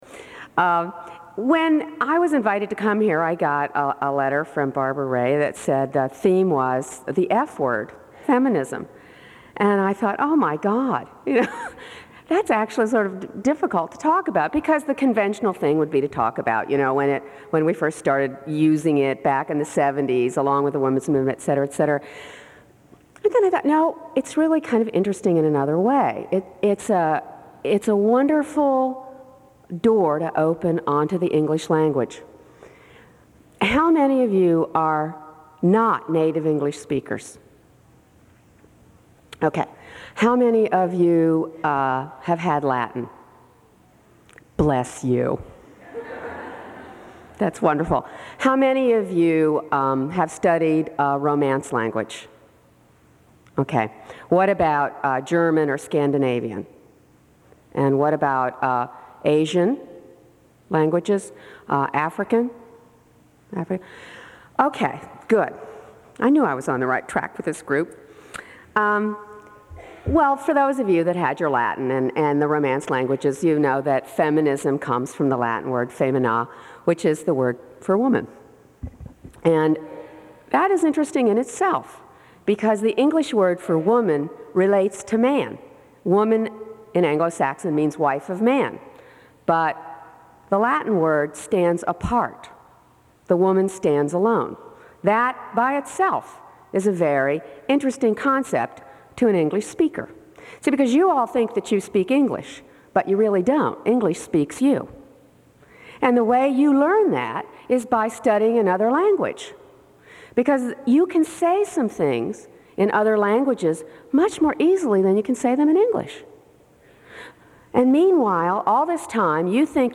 Women’s Week Keynote Lecture Address